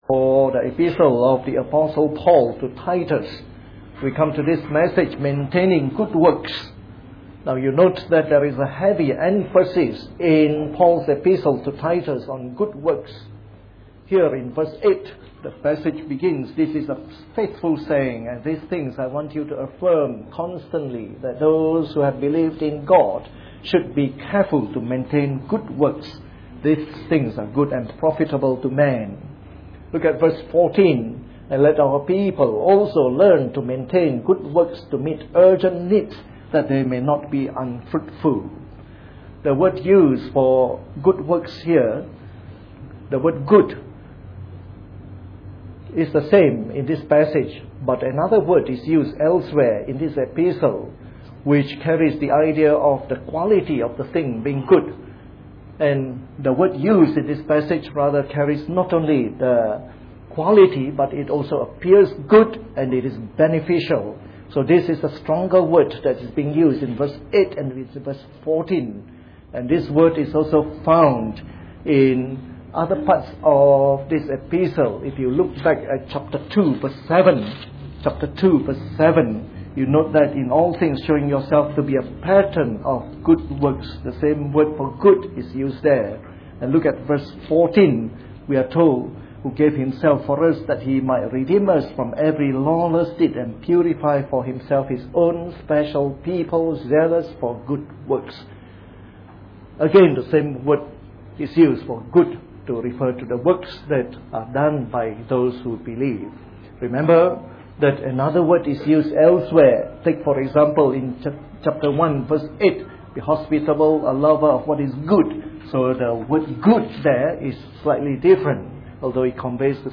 A sermon in the morning service from our series on Titus.